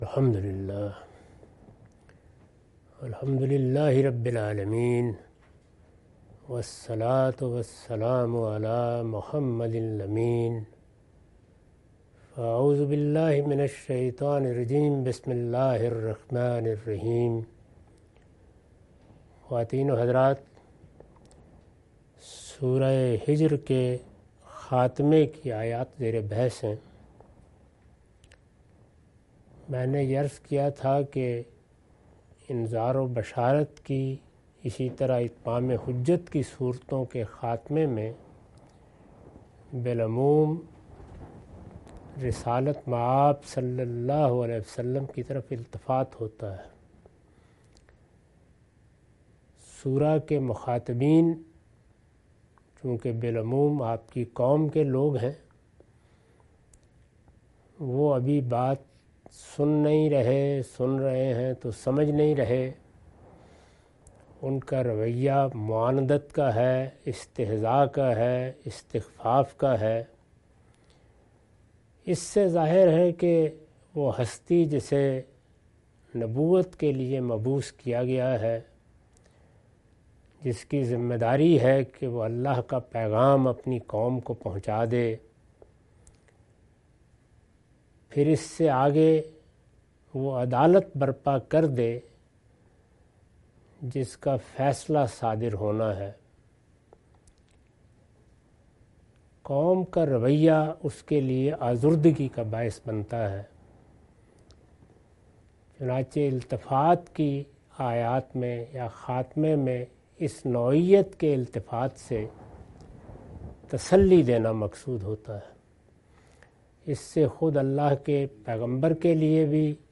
Surah Al-Hijr- A lecture of Tafseer-ul-Quran – Al-Bayan by Javed Ahmad Ghamidi. Commentary and explanation of verses 85-99.